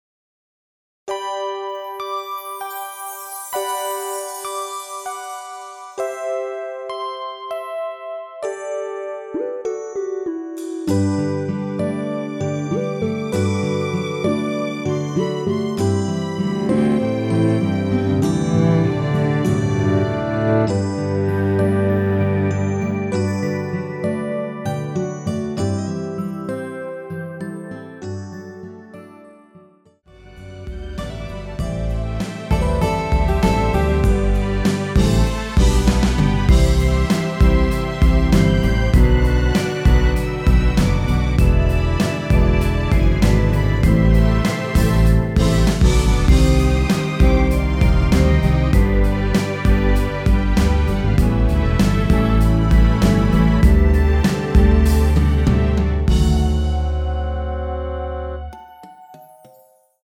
원키에서(-6)내린 멜로디 포함된 MR입니다.
앞부분30초, 뒷부분30초씩 편집해서 올려 드리고 있습니다.